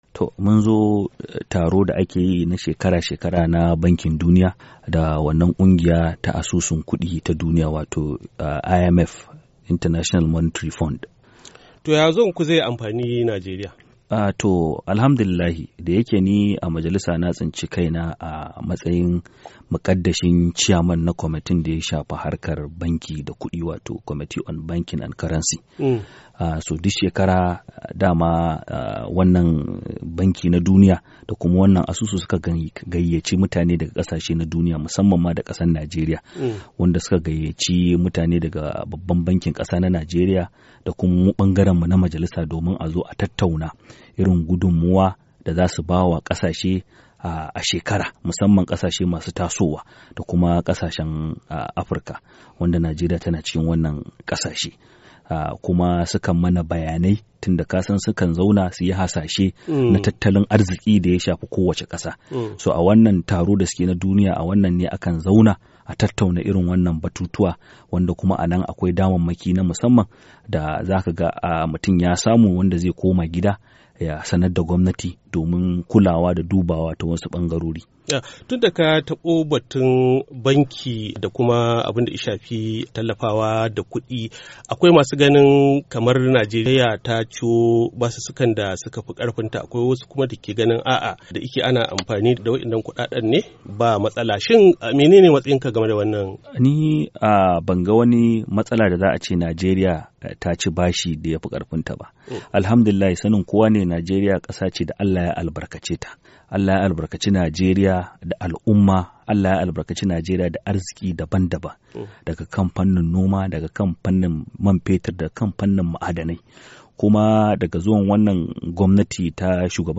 A wata hira ta musamman da yayi da Muryar Amurka, mataimakin shugaban kwamiti mai lura da harkokin bankuna da kudade na Majalisar wakilan Najeriya Hon. Hafizu Kawu, mai wakiltar karamar hukumar Tarauni a jihar Kano, ya ce akwai damarmaki na musamman a wajen wannan taron da suka gani wanda idan sun koma gida za su sanar da gwamanti shawarwari domin ta duba don inganta tattalin arzikinta.